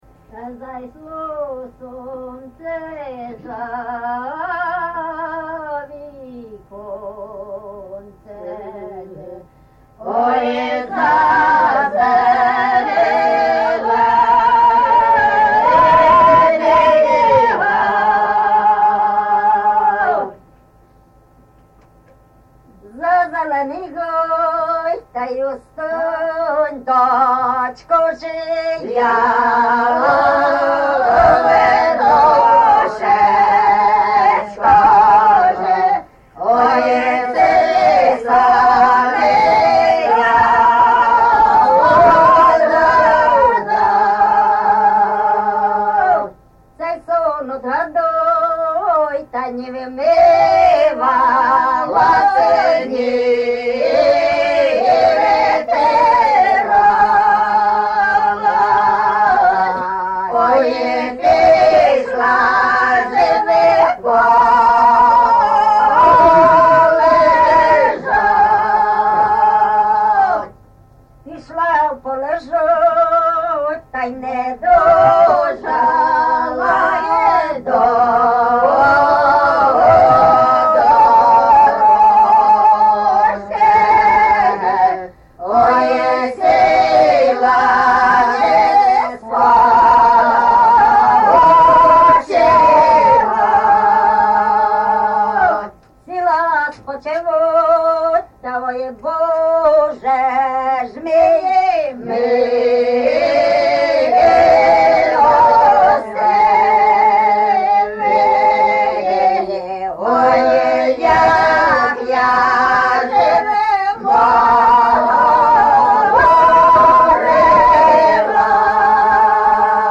ЖанрПісні з особистого та родинного життя
Місце записус. Лука, Лохвицький (Миргородський) район, Полтавська обл., Україна, Полтавщина